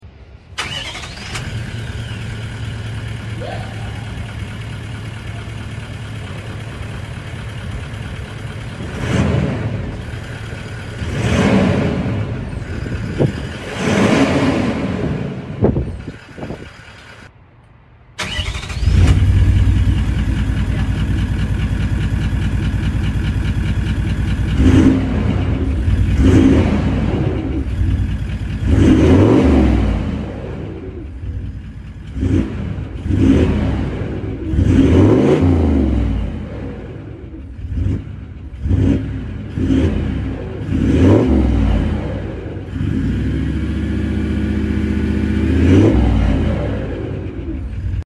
79 series upgrades exhaust to sound effects free download
79 series upgrades exhaust to monster 4” Redback System…